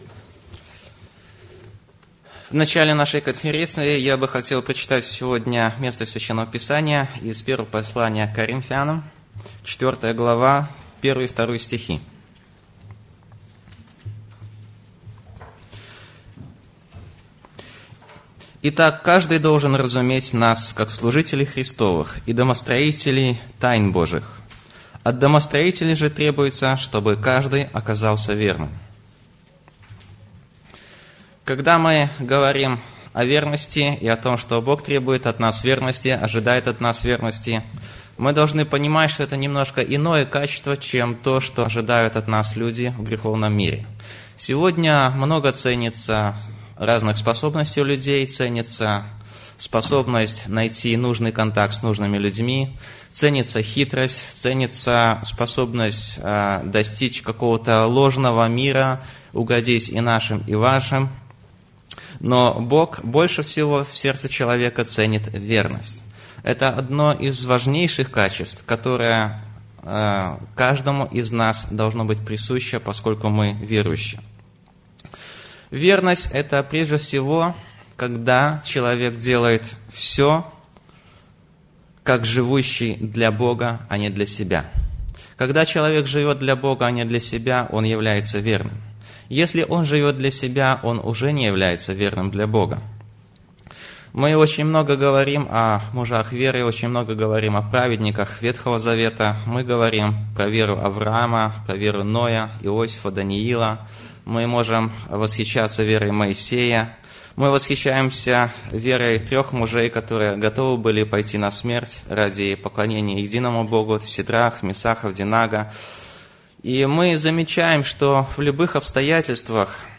Доклады:
Молодежная конференция "Верность Господу"